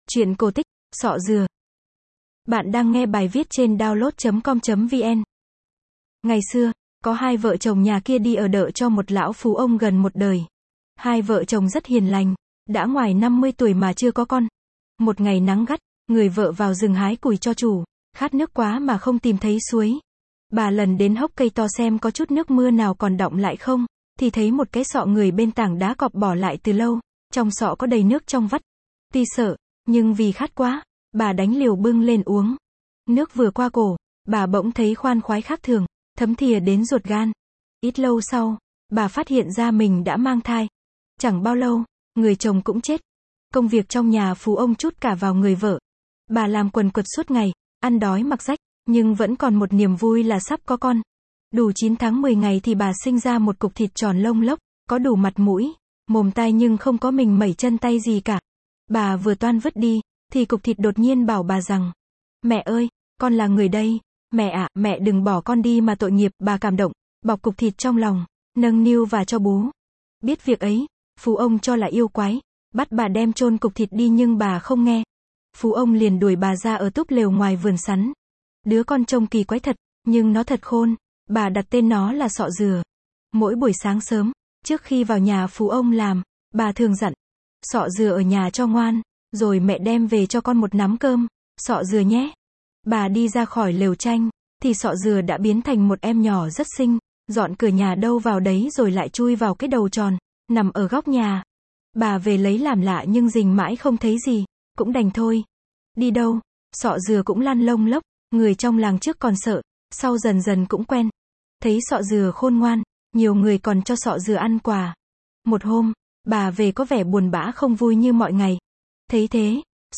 Sách nói | truyện cổ tích Sọ dừa